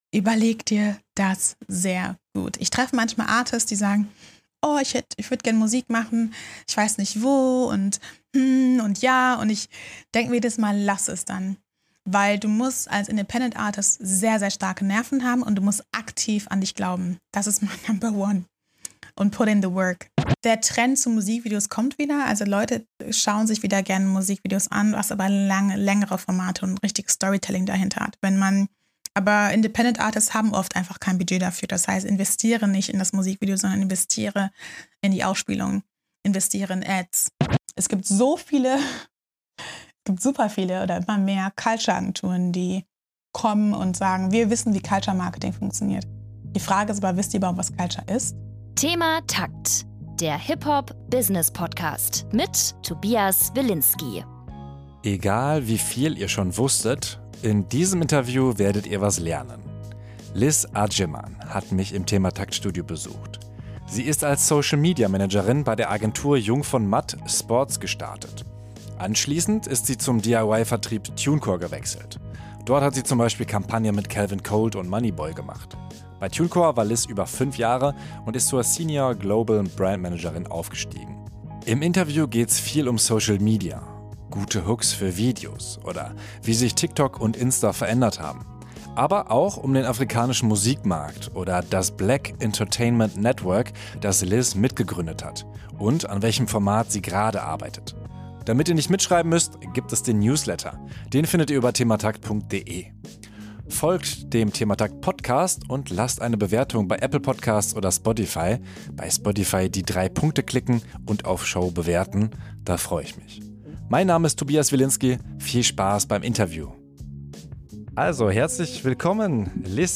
Beschreibung vor 2 Tagen Egal, wie viel ihr schon wusstet, in diesem Interview werdet ihr was lernen.